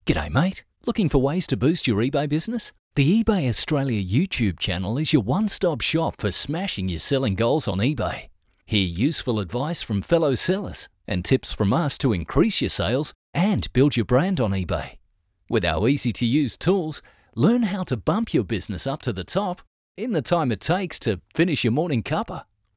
Award-winning Australian Voice. versatile, clear, and seriously experienced.
• Hip Cool
• Professional Voice booth – acoustically treated.